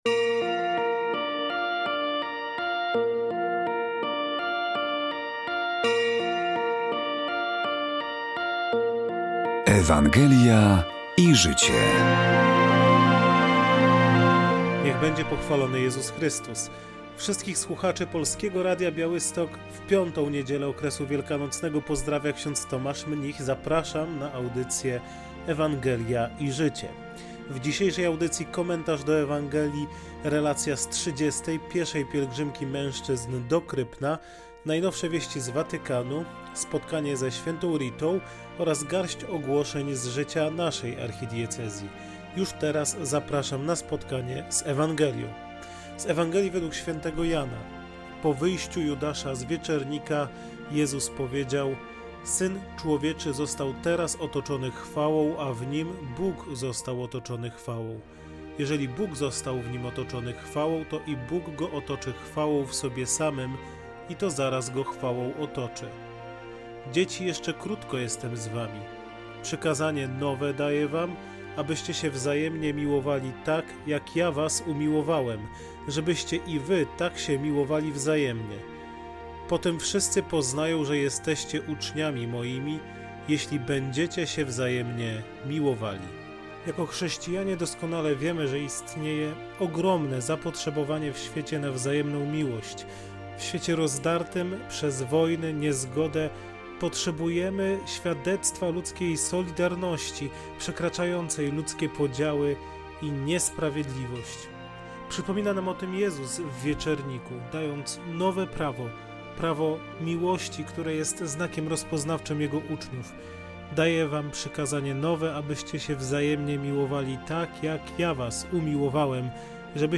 W audycji rozważanie do niedzielnej Ewangelii, relacja z 30. Pieszej Pielgrzymki Mężczyzn do Krypna, najnowsze wieści z Watykanu, spotkanie z św. Ritą oraz garść ogłoszeń z życia naszej Archidiecezji.